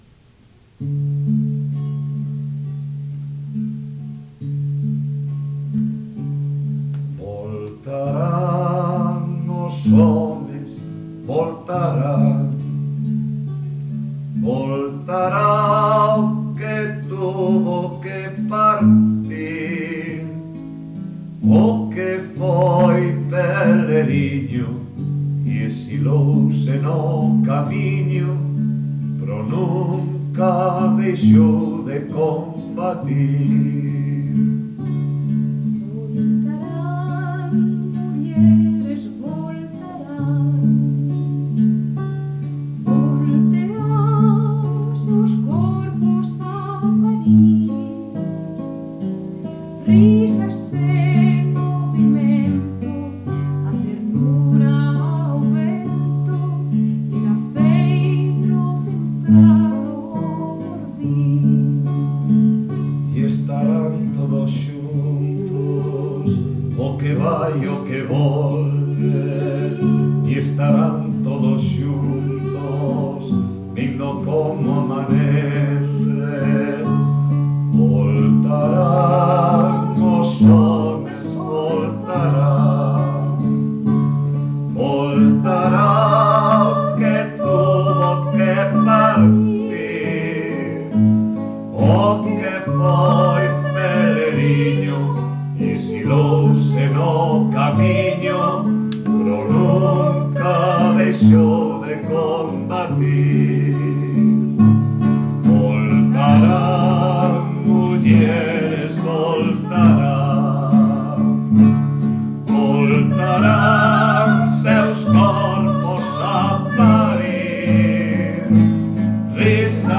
Voz